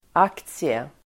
Uttal: ['ak:tsie]